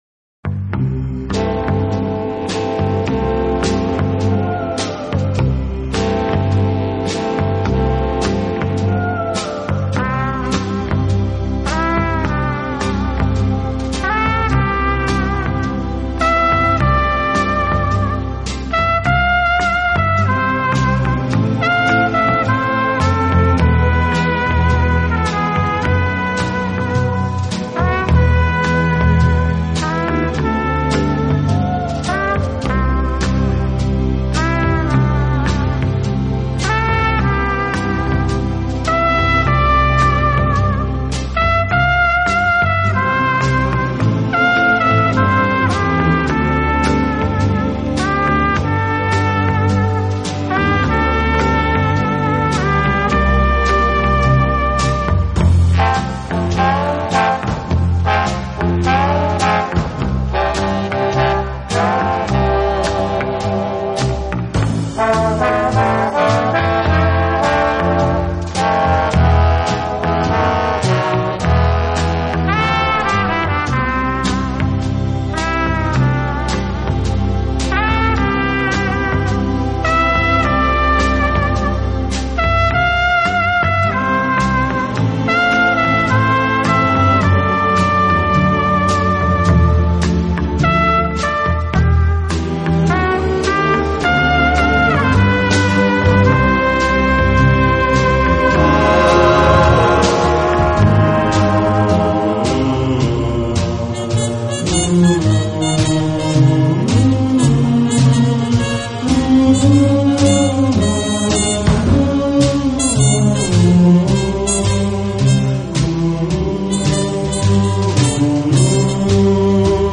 轻音乐专辑
晚这样的特殊背景，将夜的神秘、忧郁、爱的浪漫、暗淡的灯光、摇曳的舞步、梦境等等，